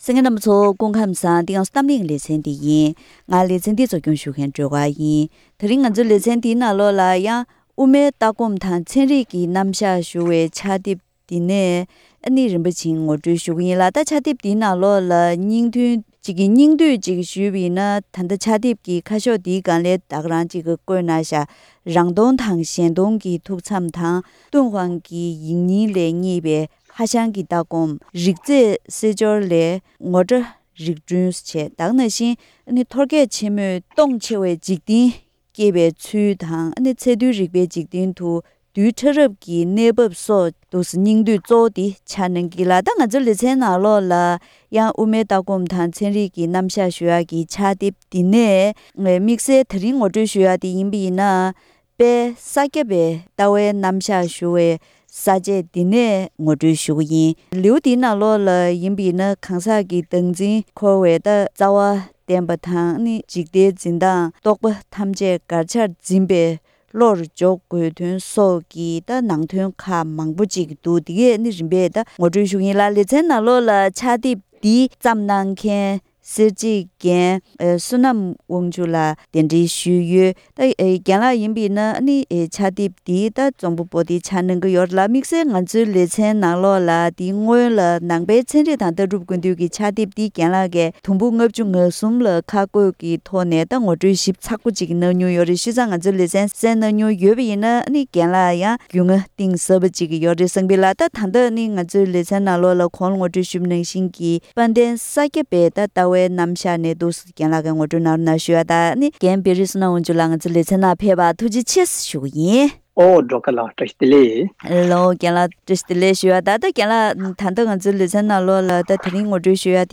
བཀའ་མོལ་ཞུས་པའི་ཐོག་ནས་ངོ་སྤྲོད་རྒྱུ་ཡིན།